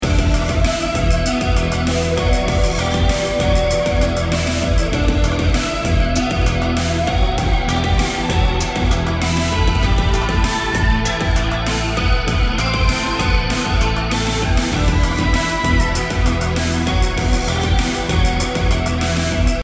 BPM 98